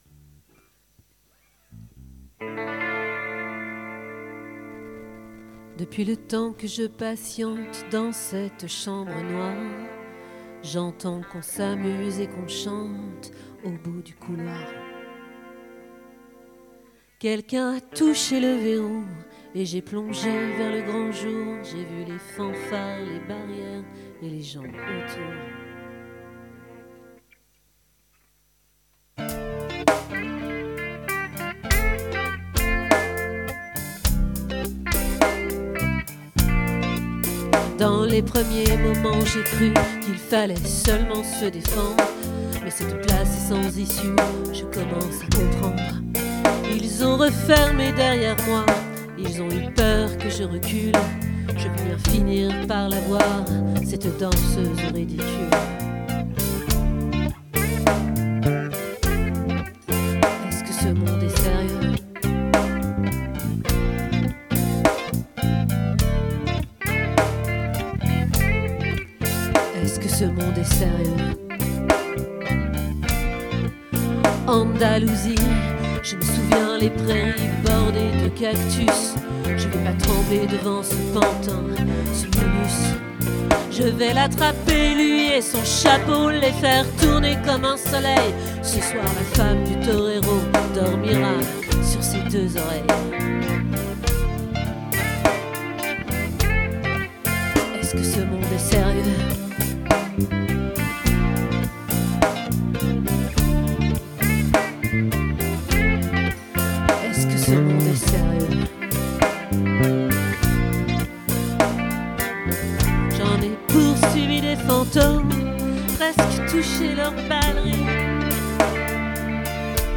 🏠 Accueil Repetitions Records_2025_02_03